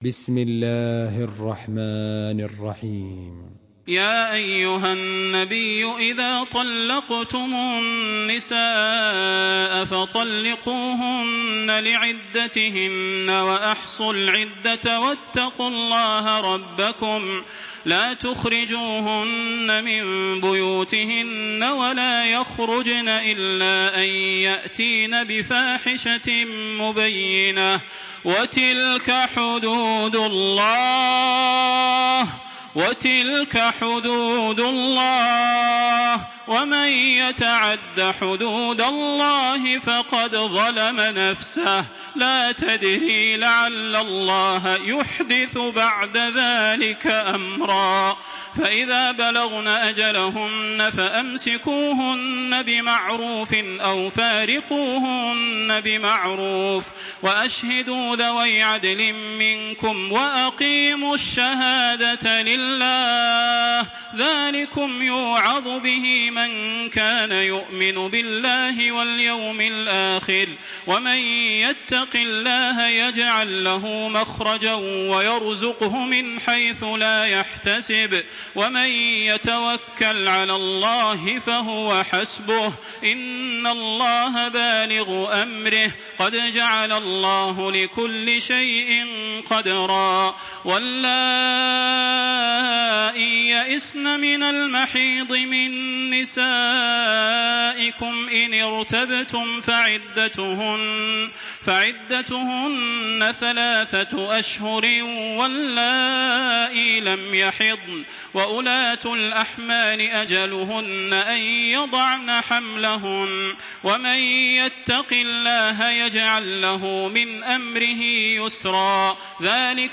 تراويح الحرم المكي 1426
مرتل